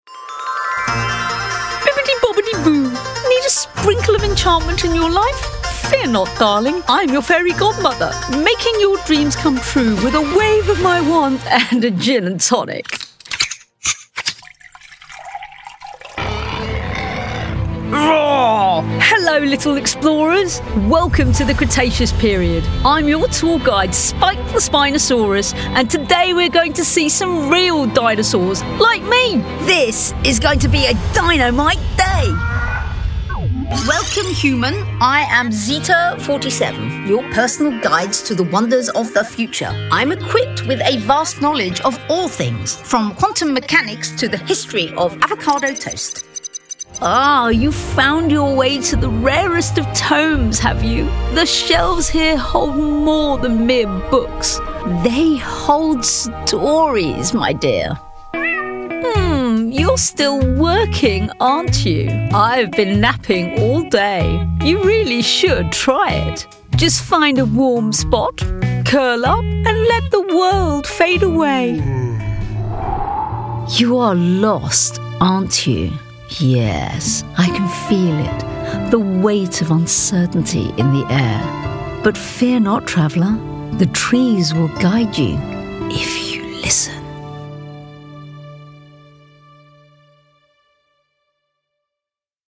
Radio & Voiceovers
I can provide voiceovers with a warm, clear and engaging voice which exudes confidence and professionalism. I have a slight maturity in my voice, as well as a youthful quality.